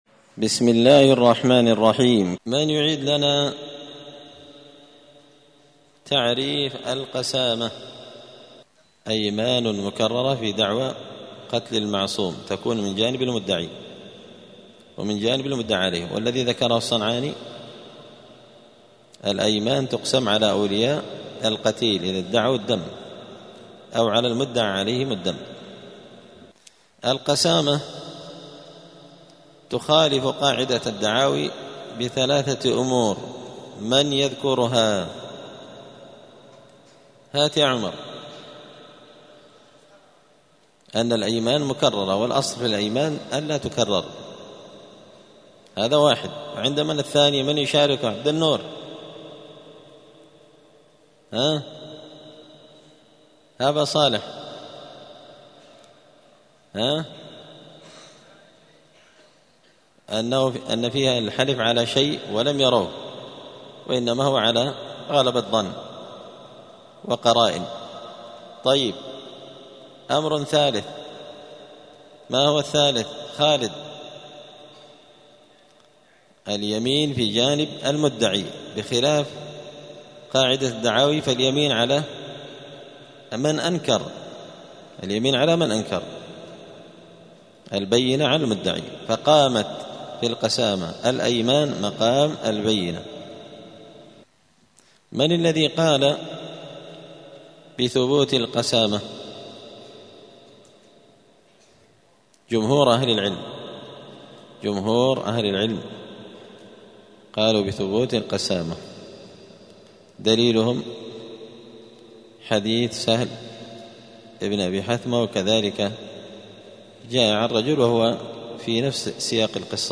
*الدرس الثامن والعشرون (28) {باب دعوى الدم والقسامة ثبوت القتل بالقسامة}*